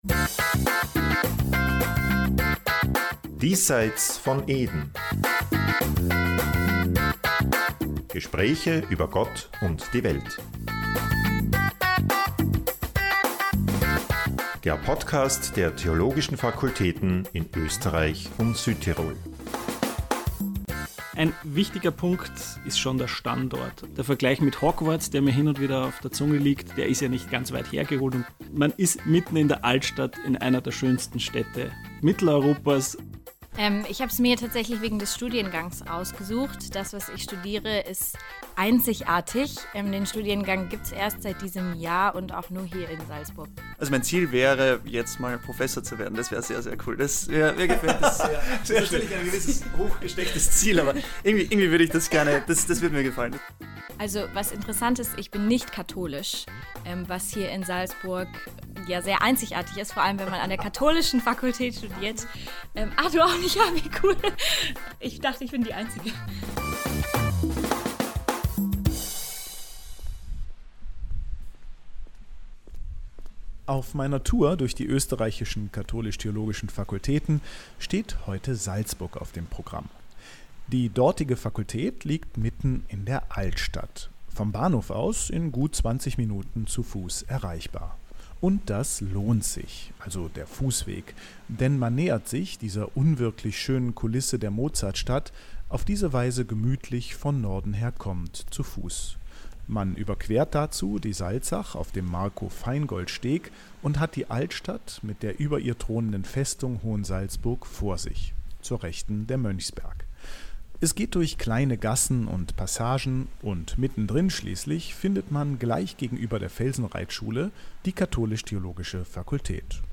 Ich habe mich in bewährter Manier verabredet mit Studierenden wie Lehrenden.
Sie treffe ich, wie es sich gehört, zwischen den Lehrveranstaltungen im Foyer der Fakultät.